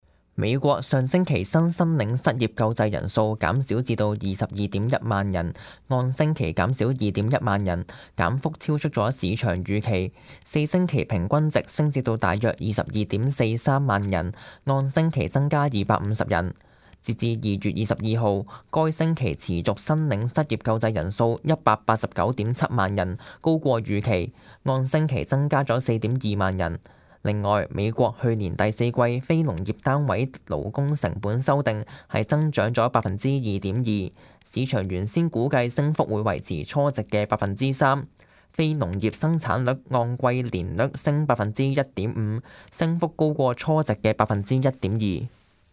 news_clip_22762.mp3